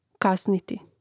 kàsniti kasniti